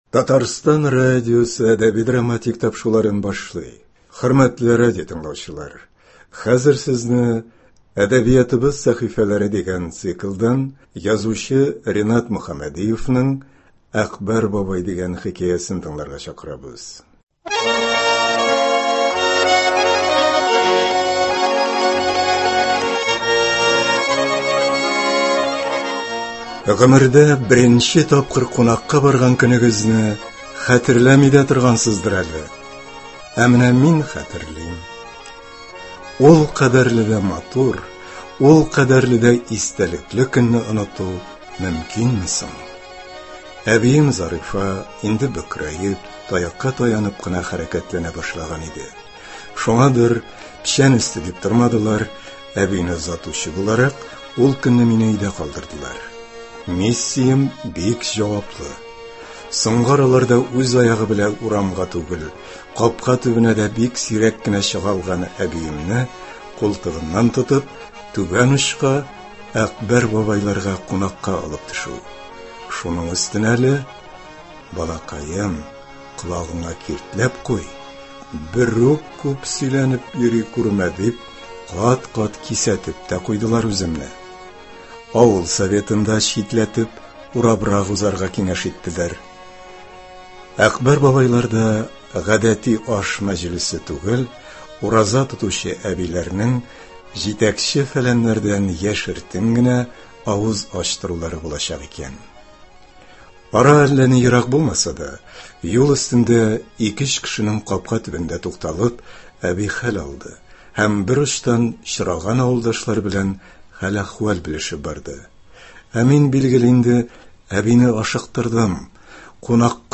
“Әкбәр бабай”. Хикәя.